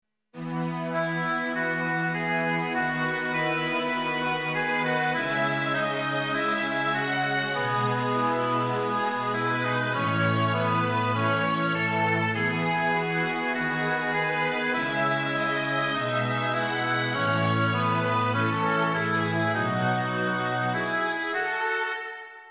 Takové útržky z klasiky.